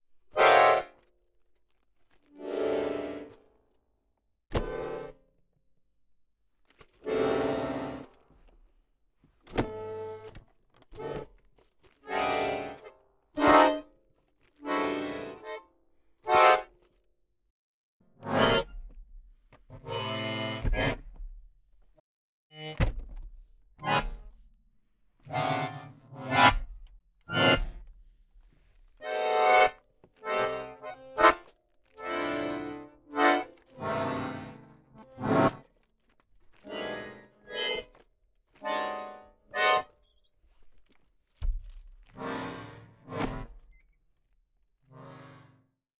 Ищу звук сломанного баяна
В файле куча разных вариантов "падения" со сжатием меха и нажатыми басами и правой клавиатурой [AUDIO][/AUDIO] Вложения Баян FX.wav Баян FX.wav 7,7 MB · Просмотры: 509